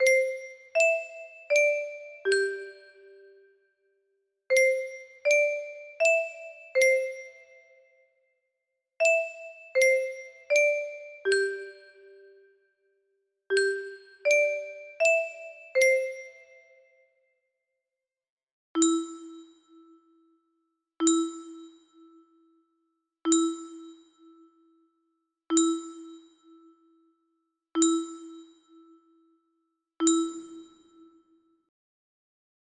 Westminster-chimes music box melody